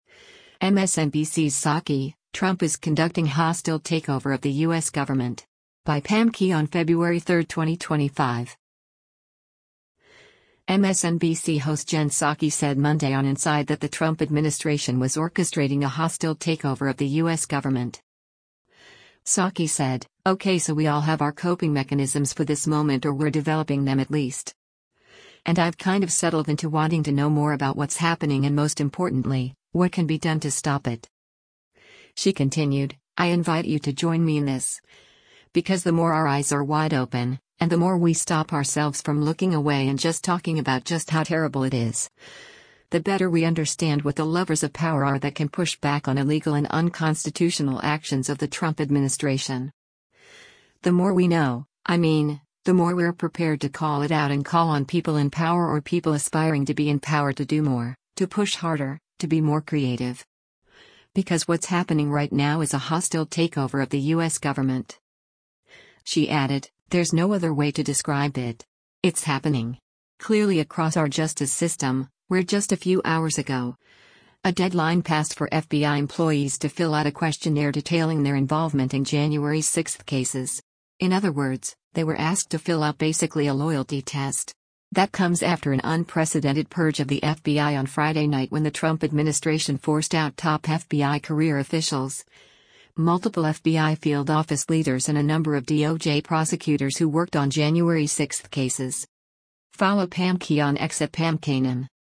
MSNBC host Jen Psaki said Monday on “Inside” that the Trump administration was orchestrating a “hostile takeover of the U.S. government.”